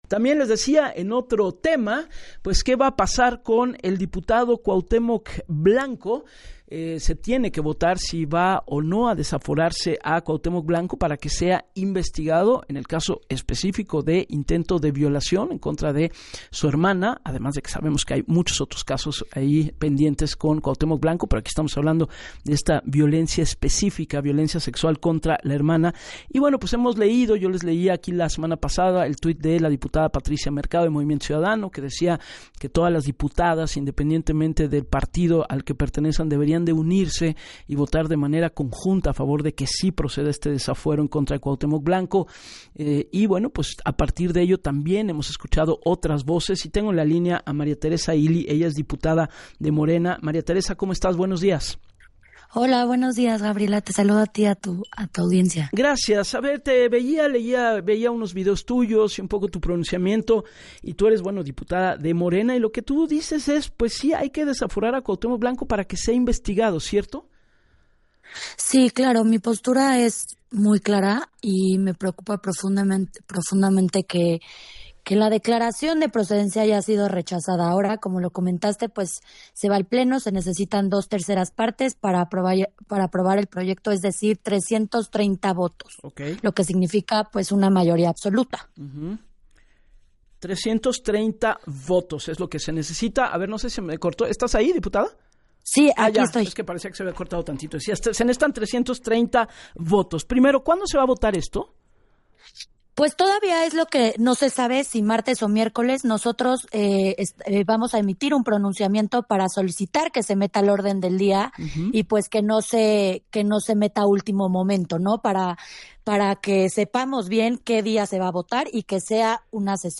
En entrevista con Gabriela Warkentin, la diputada de Morena, Teresa Ealy, habló sobre Cuauhtémoc Blanco y dijo que es una lástima que por intereses personales, aunque hay paridad en el Congreso, no se puedan unir en temas de violencia contra la mujer, “que se investigue, que se lleve a cabo un proceso como cualquier otro ciudadano, sino hizo nada, el que nada debe nada teme”, si renunciara a su fuero enviaría un mensaje del compromiso de los servidores públicos para erradicar la violencia de genero.